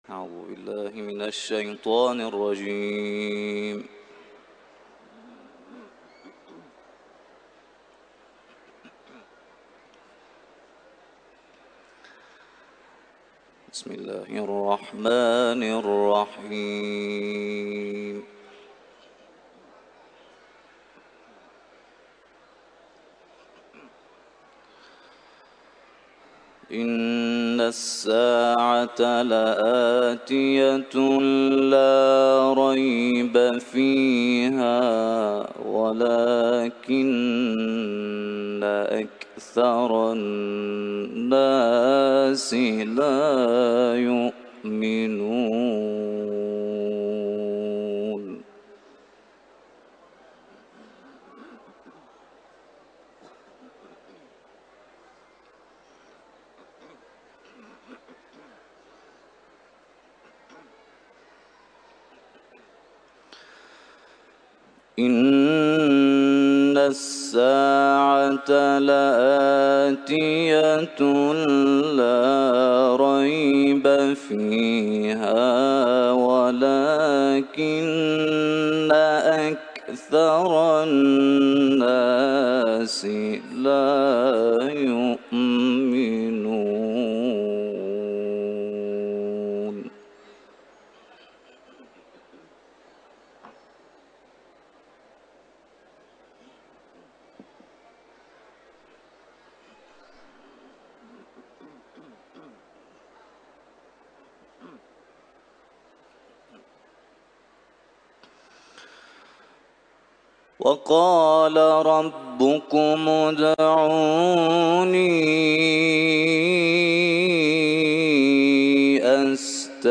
تلاوت
سوره غافر ، حرم مطهر رضوی